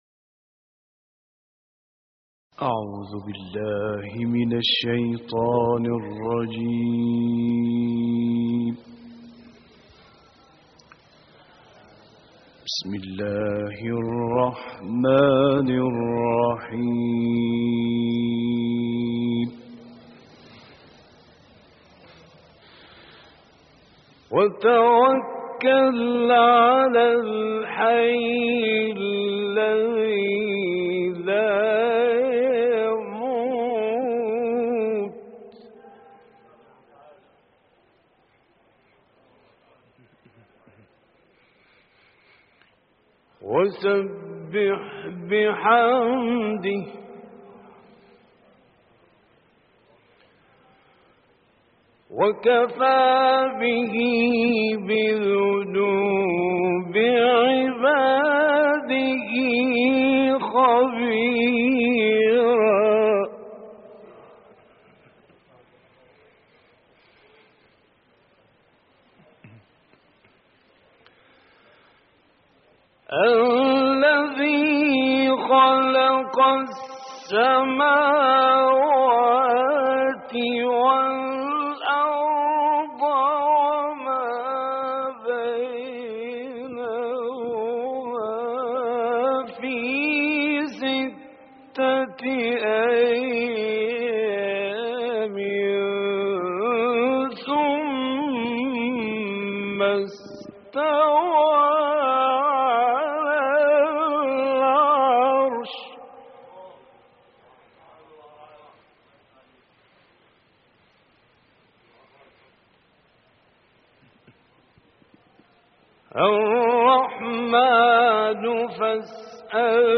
دانلود تلاوت سوره فرقان آیات 58 تا آخر - استاد غلوش